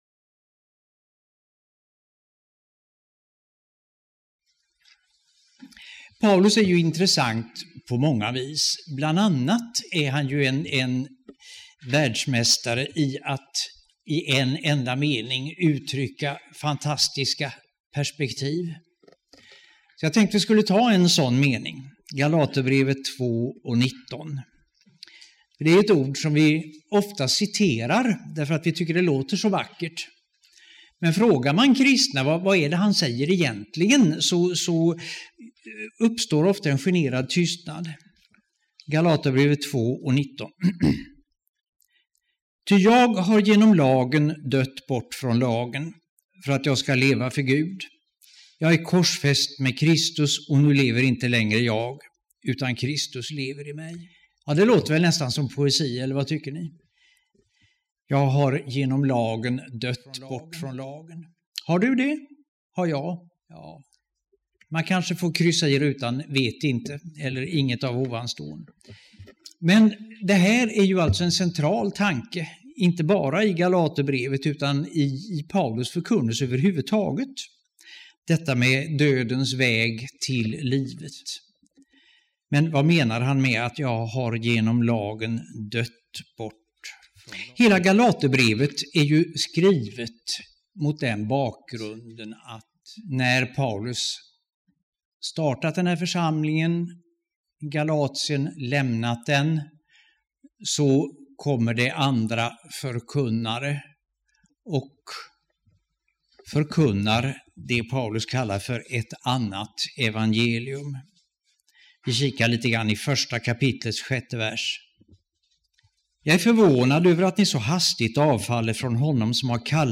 2007-04-14 Bibelstudium av